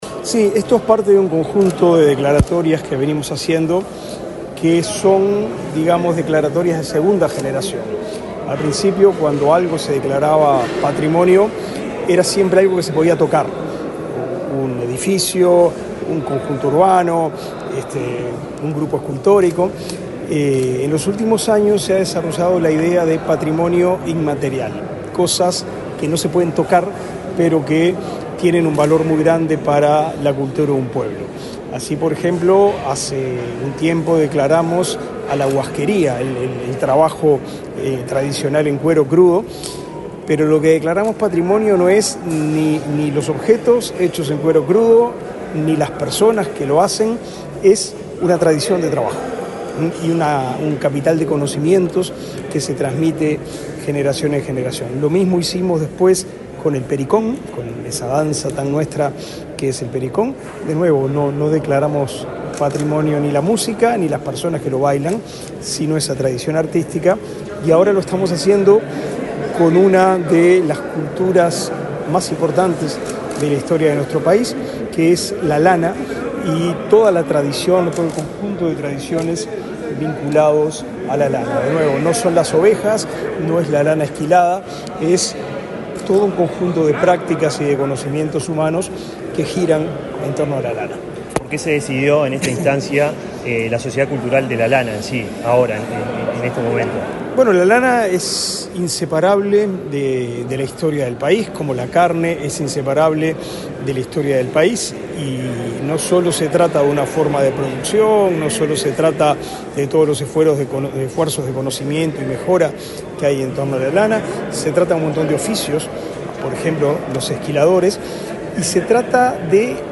Entrevista al ministro de Educación y Cultura, Pablo da Silveira